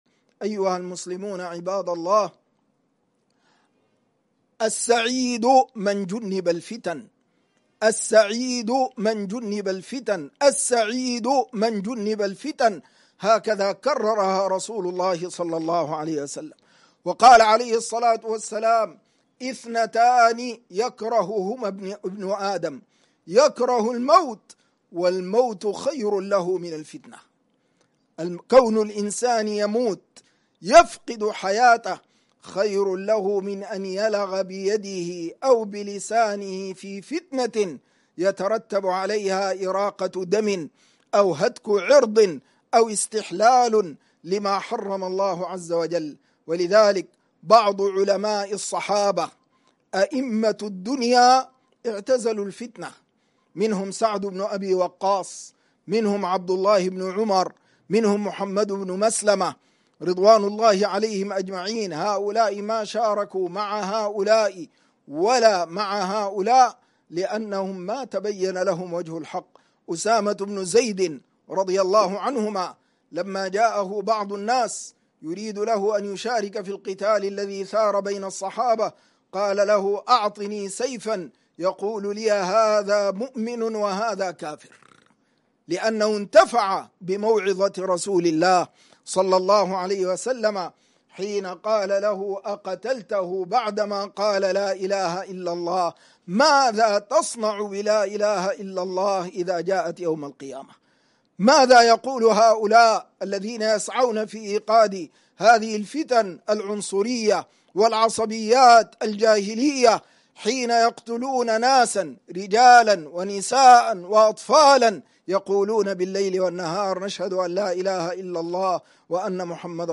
السعيد من اجتنب الفتن من خطبة الجمعة - الشيخ عبد الحي يوسف عبد الرحيم